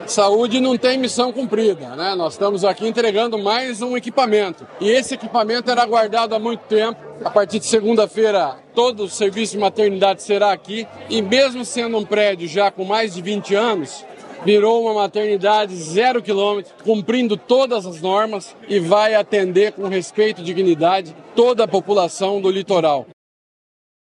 Sonora do secretário da Saúde, Beto Preto, sobre a inauguração da nova Maternidade de Paranaguá
SONORA BETO PRETO - MATERNIDADE PARANAGUÁ.mp3